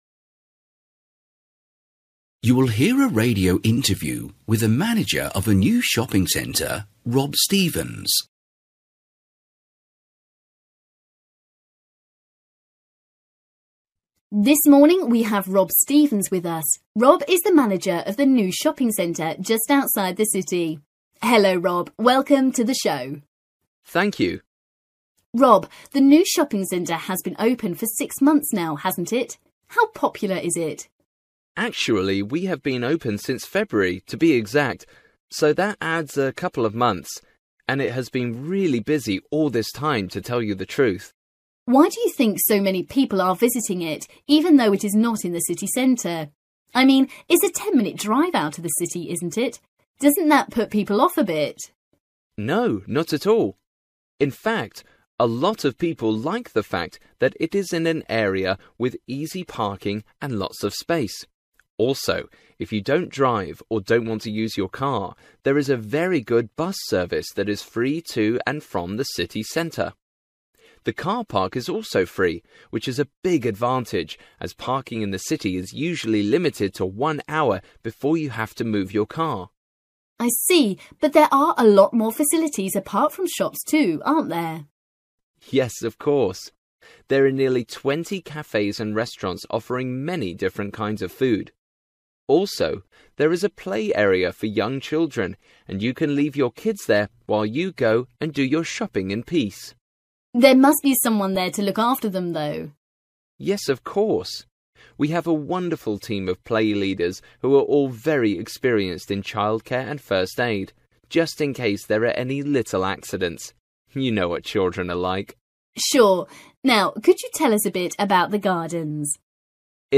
Listening: the manager of a new shopping centre